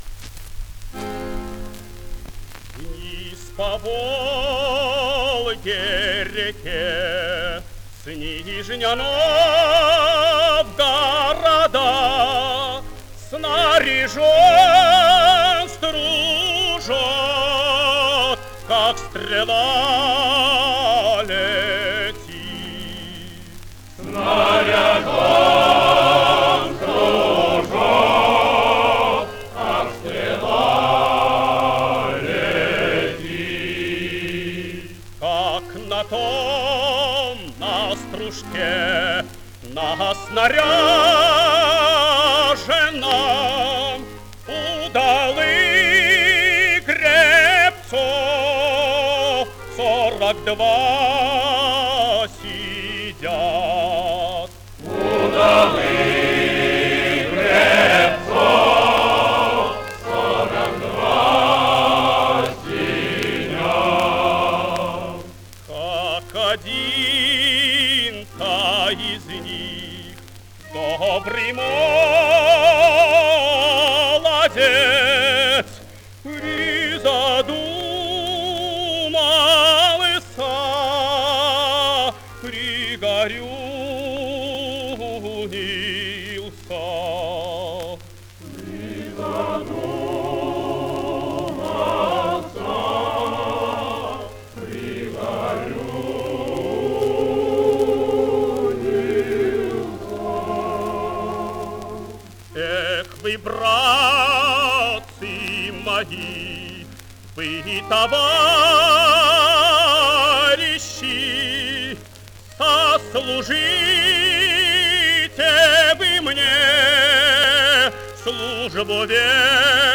Обладал сильным лирико-драматическим тенором широкого диапазона, мягкого тембра с характерной окраской, драматическим и комедийным талантом.
Русская народная песня «Вниз по Волге-реке».
Солист С. Н. Стрельцов.